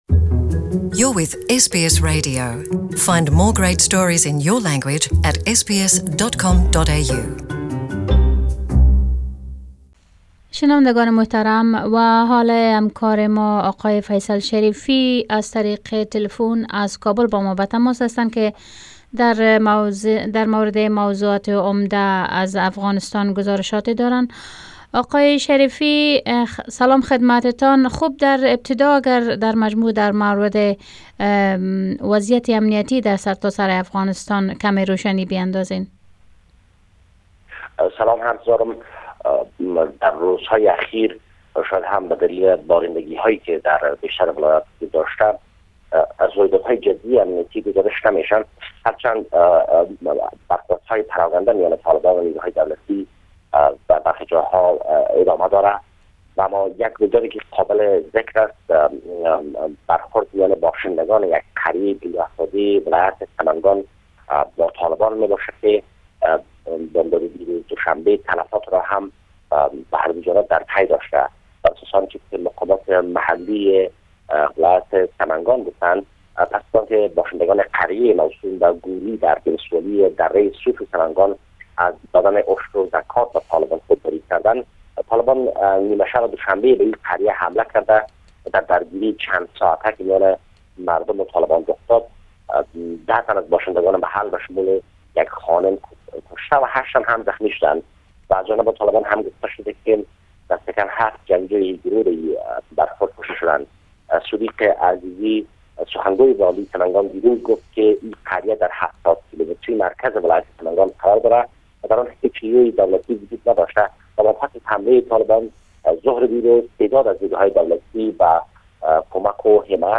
Report from Afghanistan 05/02